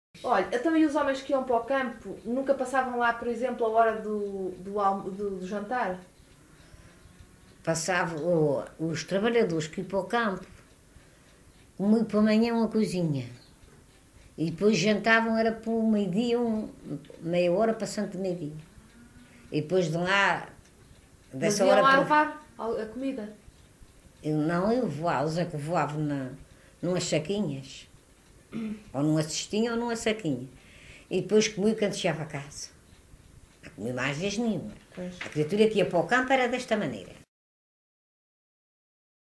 LocalidadeFontinhas (Praia da Vitória, Angra do Heroísmo)